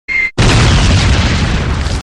File Category : Free mobile ringtones > > Sms ringtones